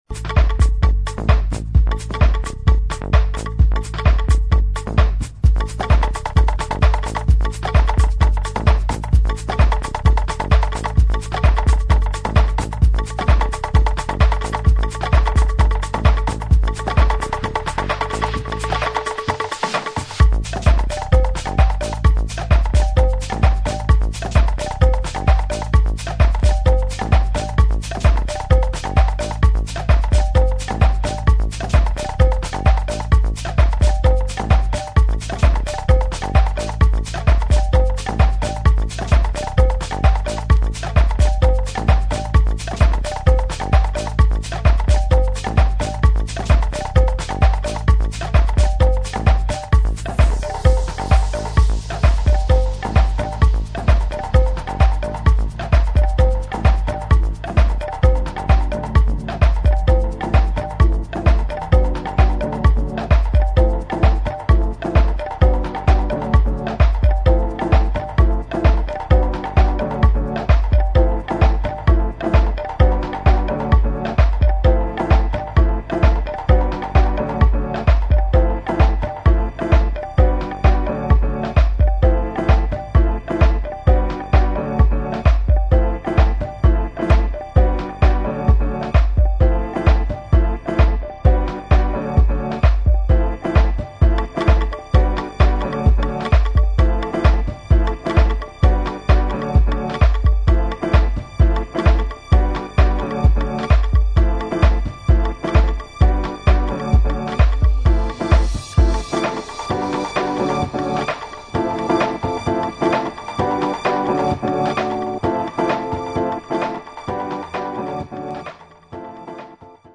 [ AFRO BEAT / WORLD / DEEP HOUSE ]